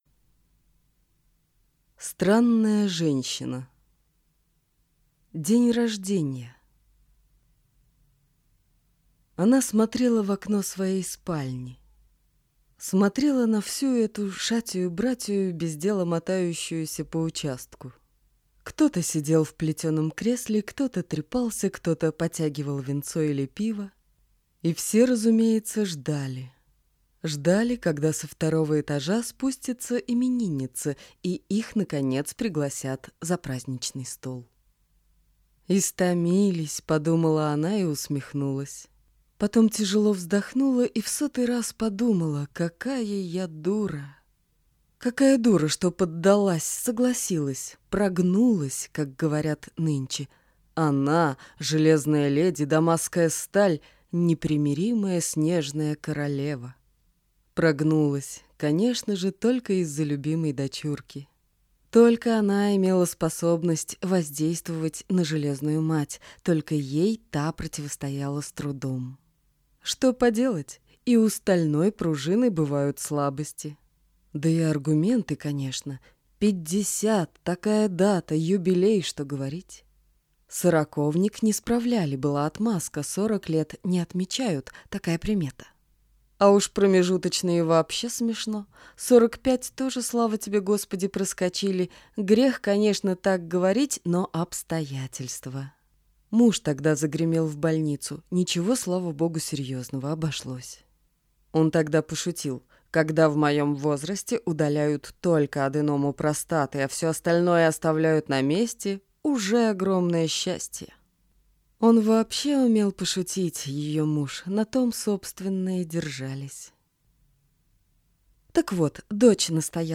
Аудиокнига Странная женщина | Библиотека аудиокниг